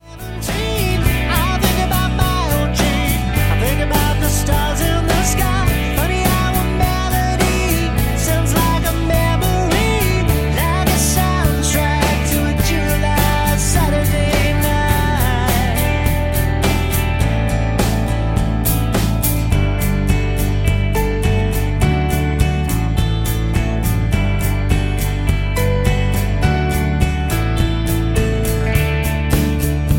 D
Backing track Karaoke
Country, 2010s